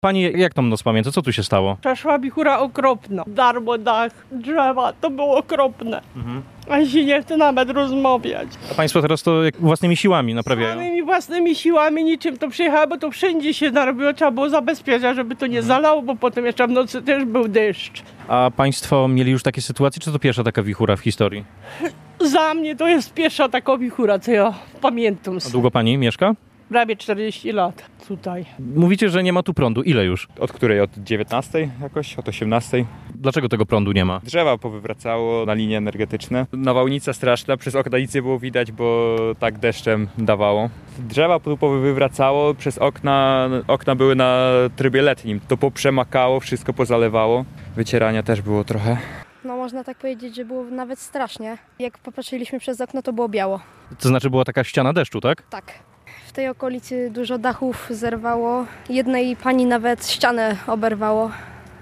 To trwało moment, kilka kilkanaście minut, ściana deszczu – mówili w rozmowie z naszym reporterem mieszkańcy Głuchowa, opisując przebieg burz.